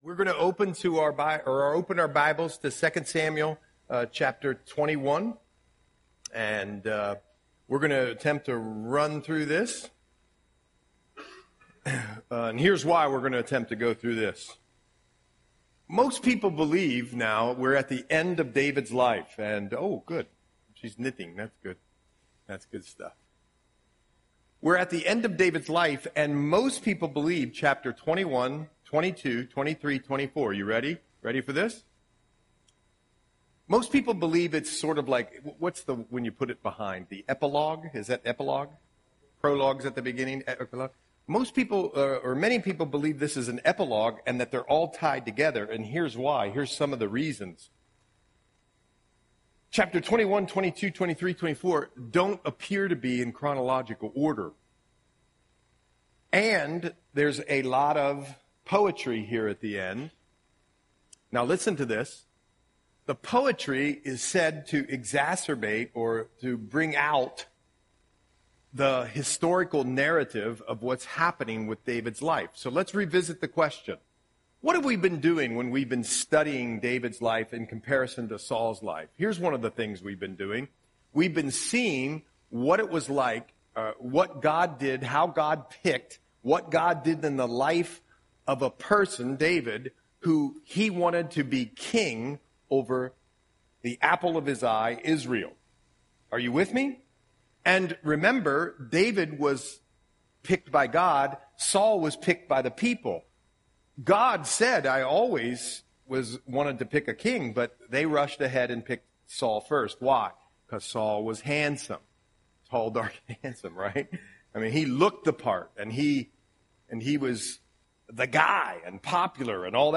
Audio Sermon - October 2, 2024